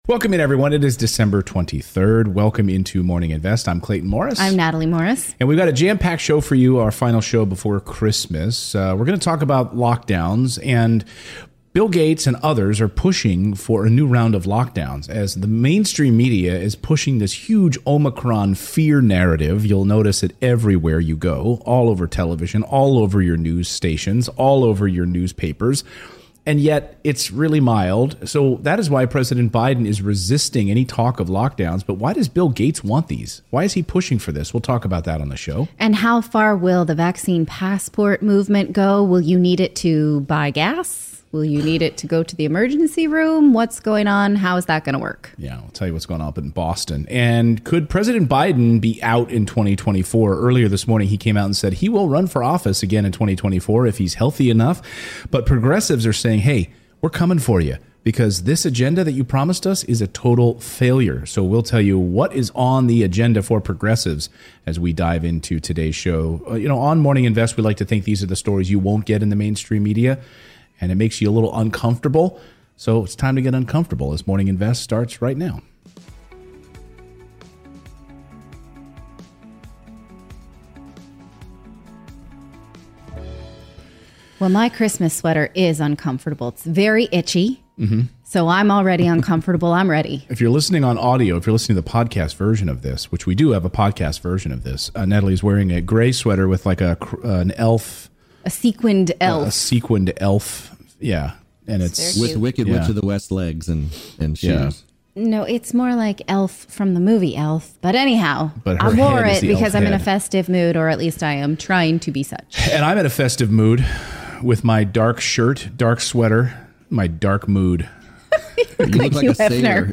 In today's livestream... today we're covering: